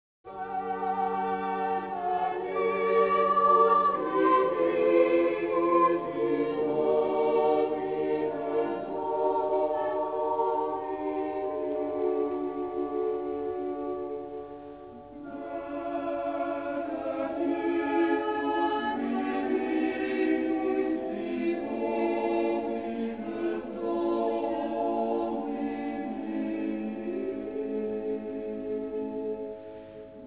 trumpet
trombone
organ
cello
double-bass